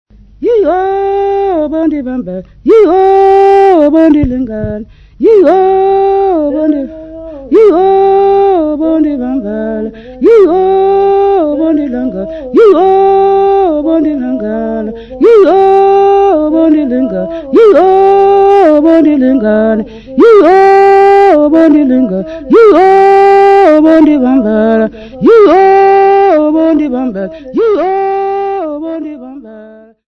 Folk music
Folk songs, Xhosa
Field recordings
Africa South Africa Mount Aylff f-sa
sound recording-musical
Indigenous music.